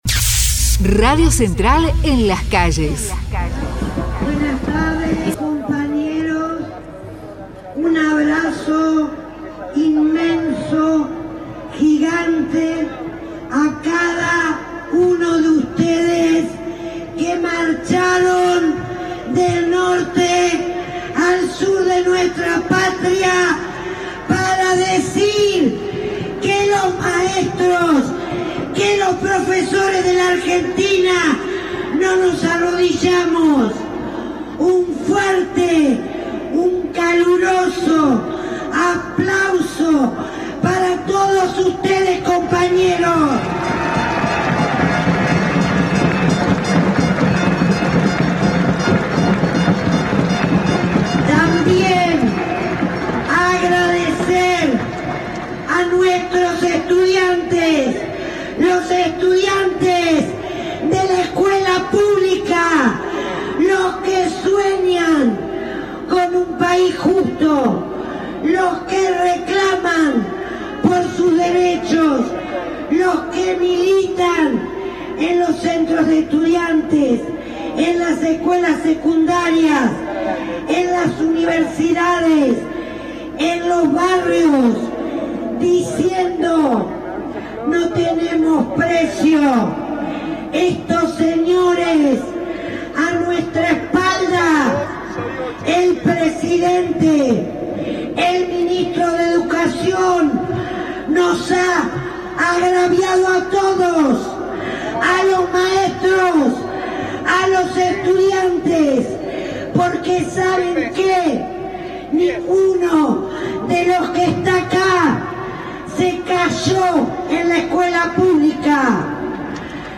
en el cierre de la Marcha Federal Educativa
MARCHA FEDERAL EDUCATIVA: Discurso